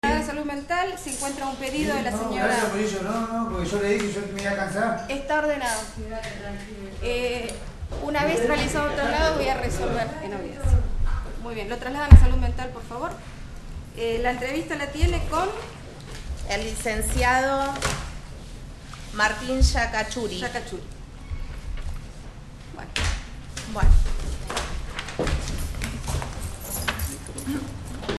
06_-_JUEZ_ORDENA_SE_TRASLADE.mp3